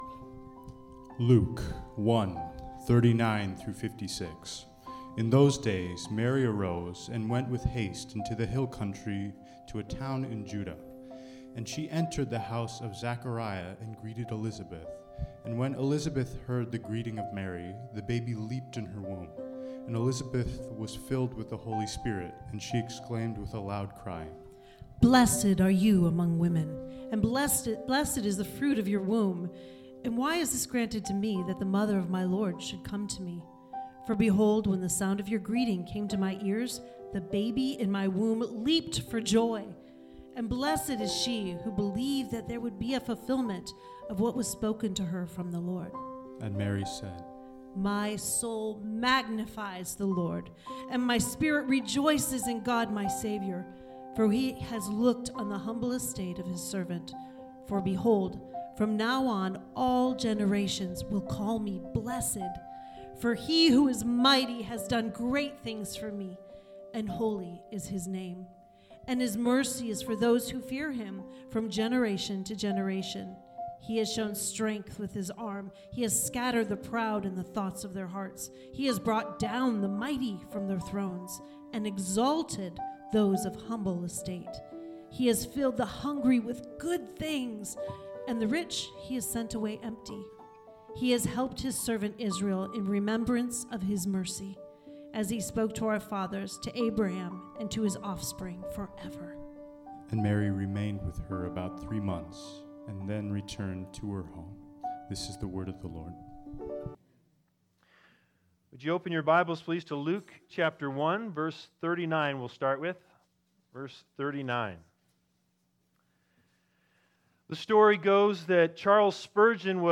Luke 1:39-56 Service Type: Christmas The Big Idea: The "fruit" of Mary's womb is the "root" of all blessing.